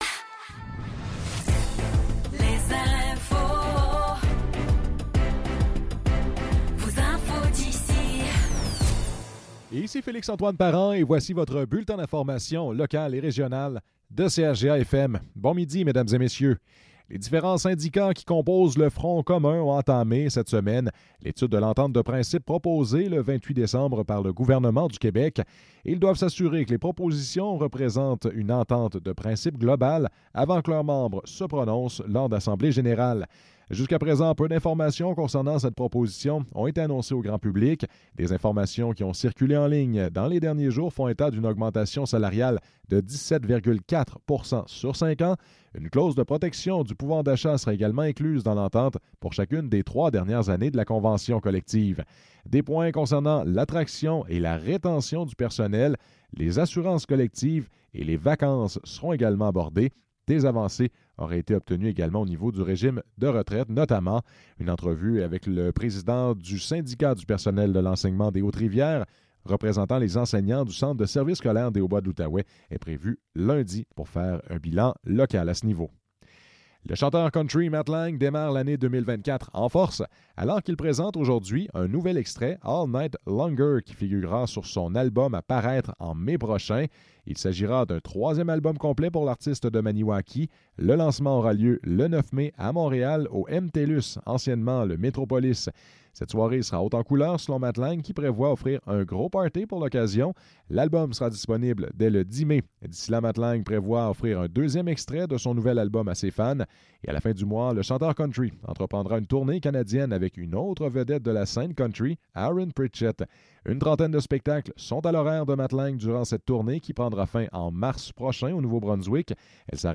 Nouvelles locales - 5 janvier 2024 - 12 h